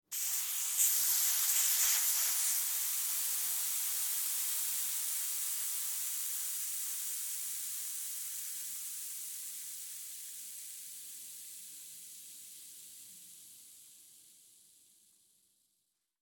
Кислород медленно покидает емкость